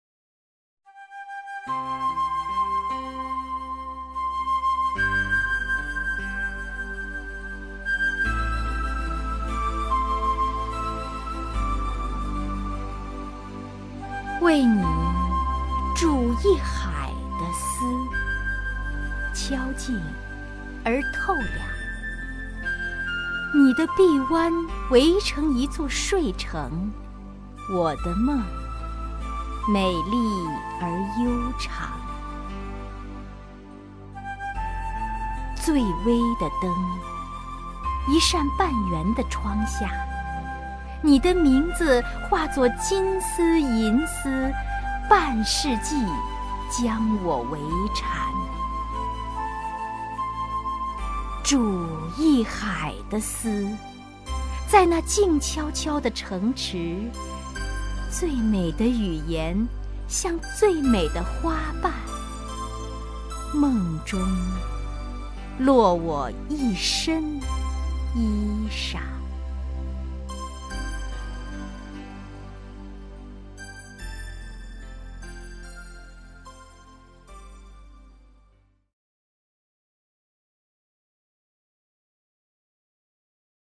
首页 视听 名家朗诵欣赏 王雪纯
王雪纯朗诵：《怀人》(敻虹)